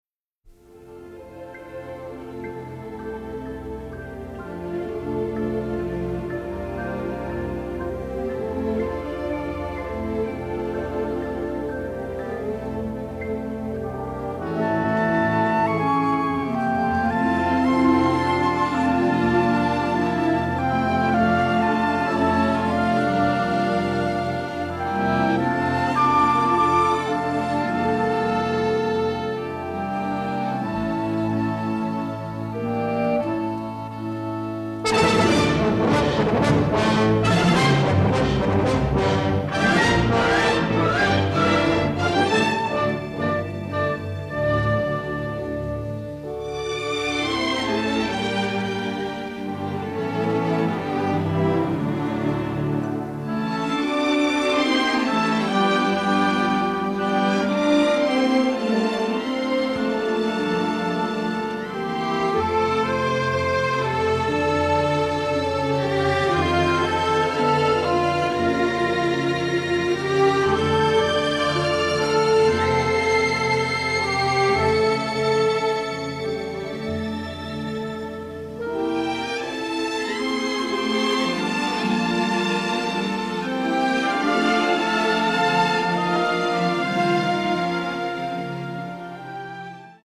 robust orchestral score